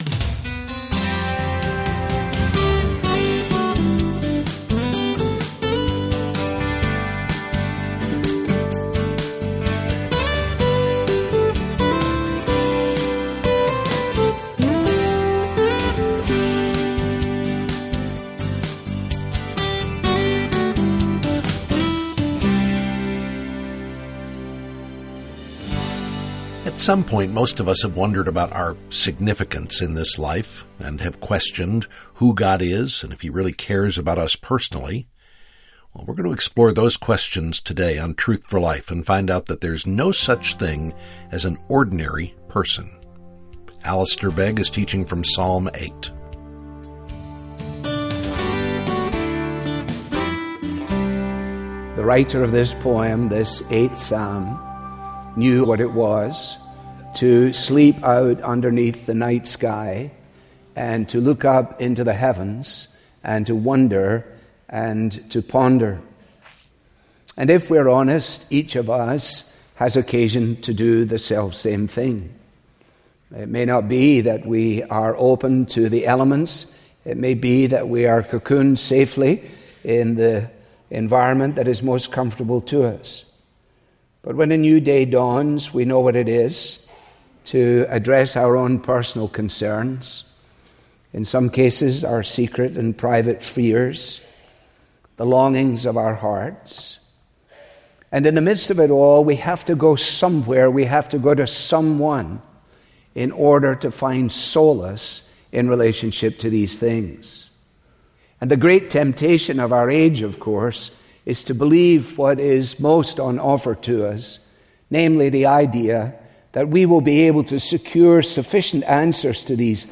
This listener-funded program features the clear, relevant Bible teaching